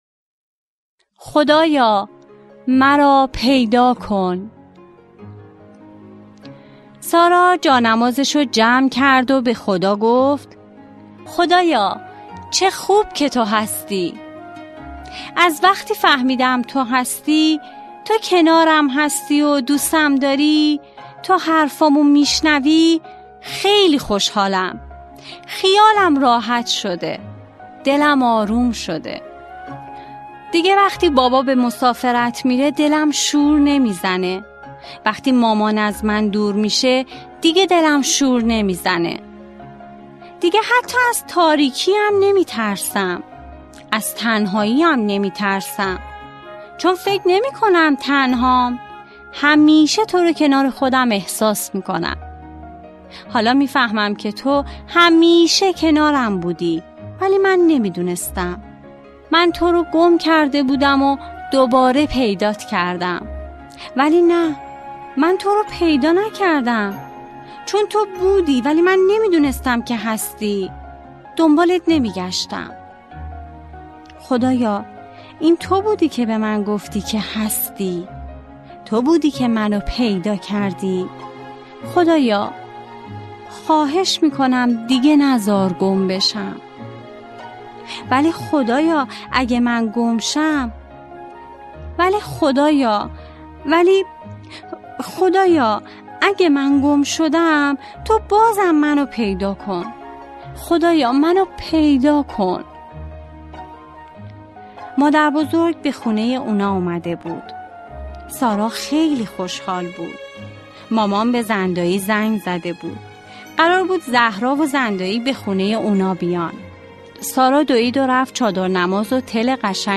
قصه های کودکانه نماز کودکانه داستان های صوتی قصه های چادر نماز مرکز تخصصی نماز تربت امام حسین اولین تسبیح